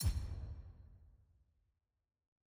sfx_ui_landing_play.ogg